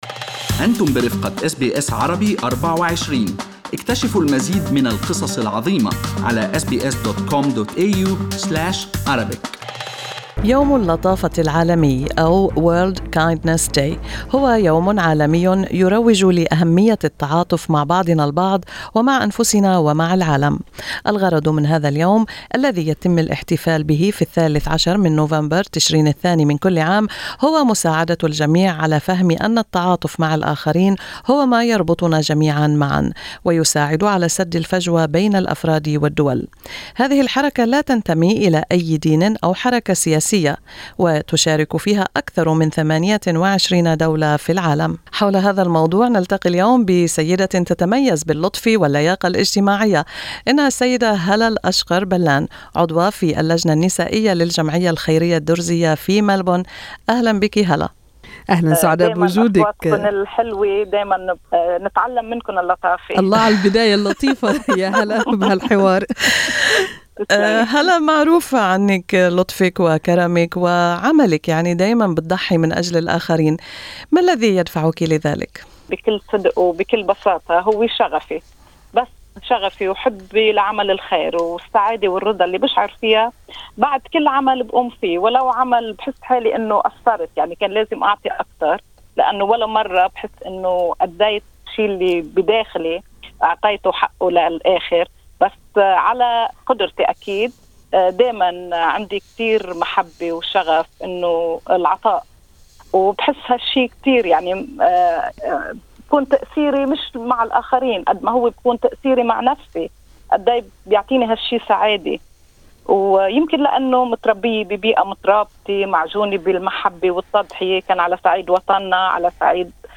لقاء مع أس بي أس